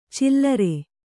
♪ cillare